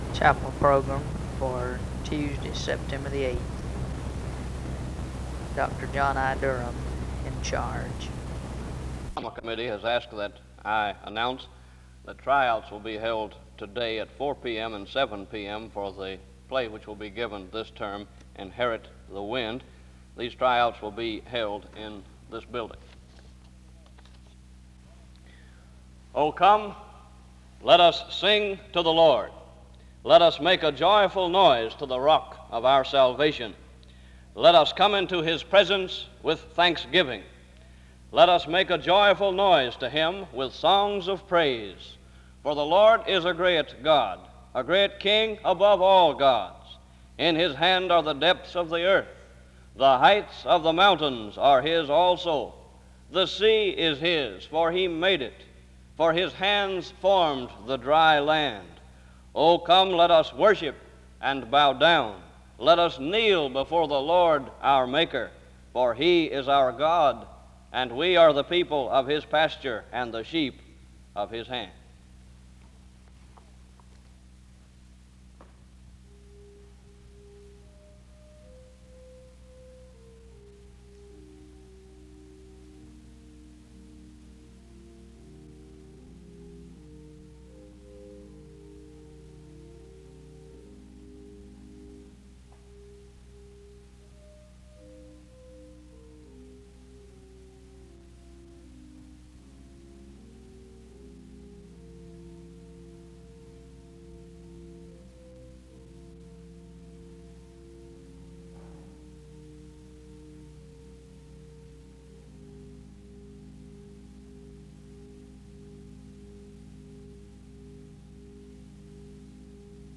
The service begins with announcements and scripture reading from 0:00-1:12. Music plays from 1:16-5:35.